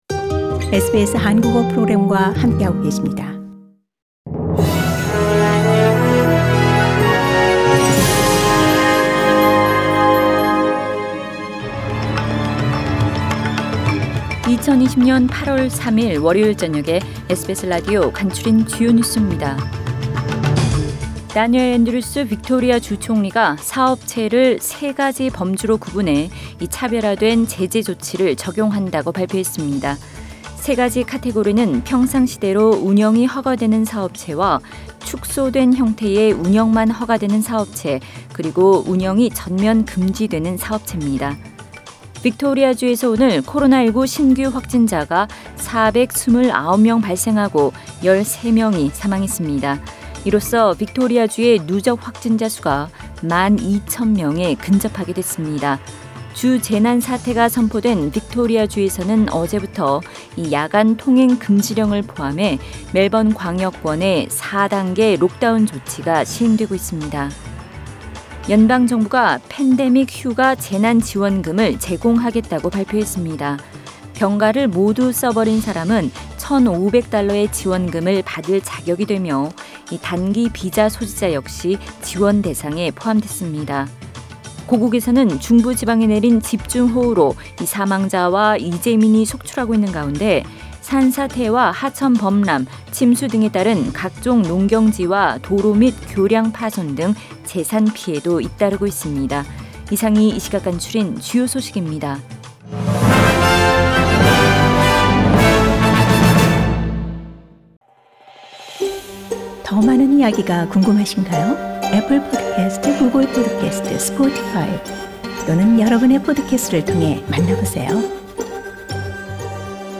SBS 한국어 뉴스 간추린 주요 소식 – 8월 3일 월요일
2020년 8월 3일 월요일 저녁의 SBS Radio 한국어 뉴스 간추린 주요 소식을 팟 캐스트를 통해 접하시기 바랍니다.